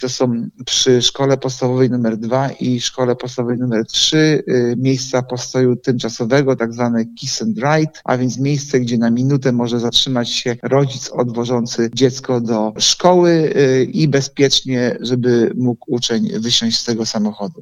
– To między innymi projekt KISS&RIDE – mówi zastępca prezydenta Ełku Artur Urbański.